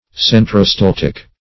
Search Result for " centrostaltic" : The Collaborative International Dictionary of English v.0.48: Centrostaltic \Cen`tro*stal"tic\, a. [Gr.